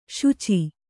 ♪ śuci